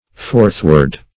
forthward - definition of forthward - synonyms, pronunciation, spelling from Free Dictionary Search Result for " forthward" : The Collaborative International Dictionary of English v.0.48: Forthward \Forth"ward\, adv.